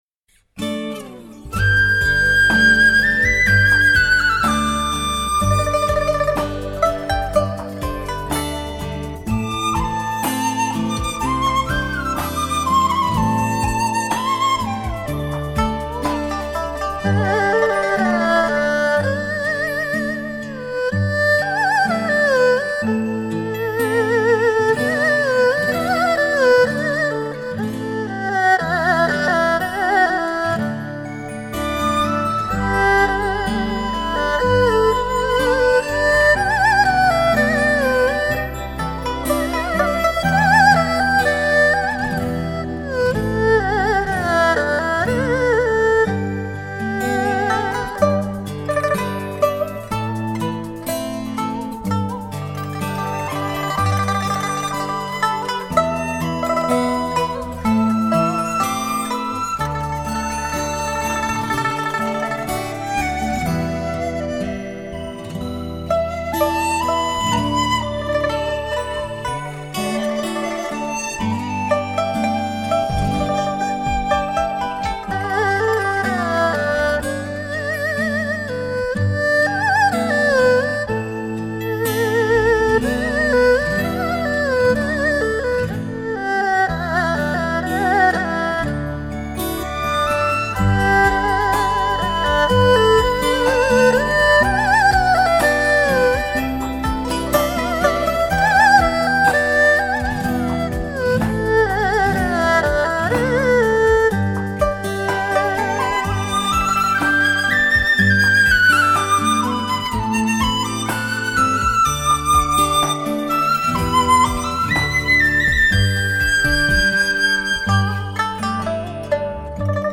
乐器演奏系列